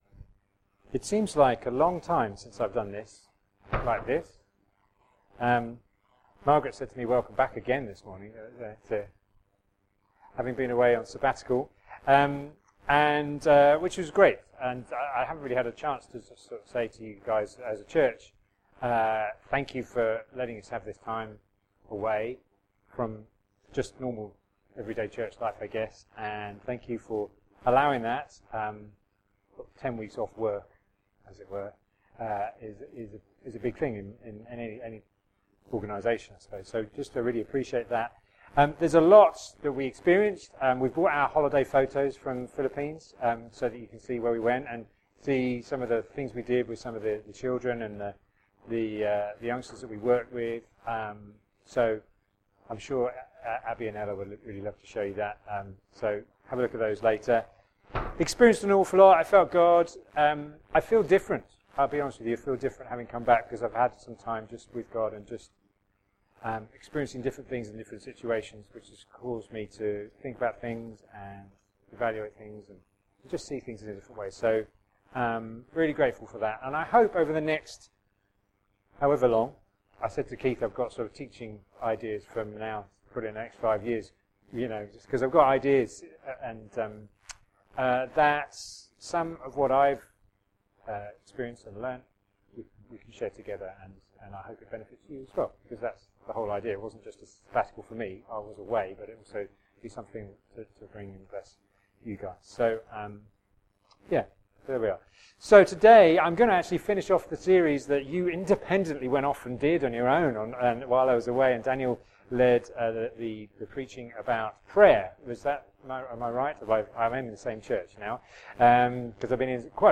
Preaching4-11-18.mp3